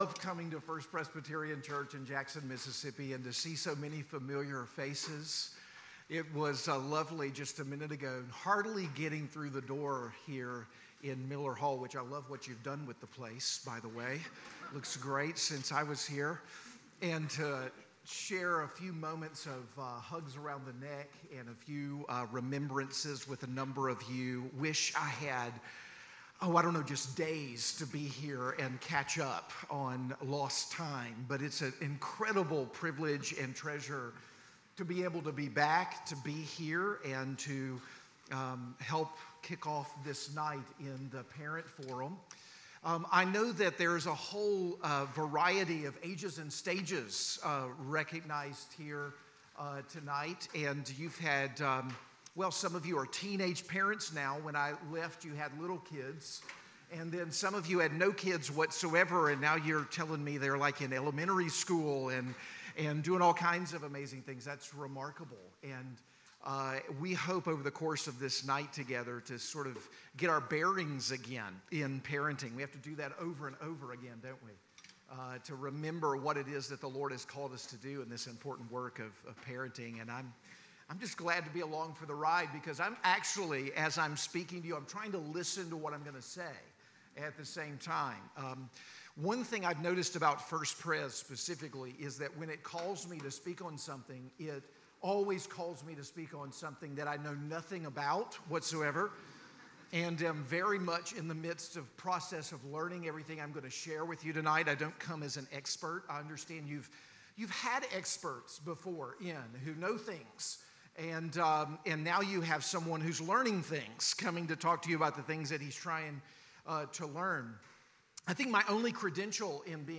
Keynote Address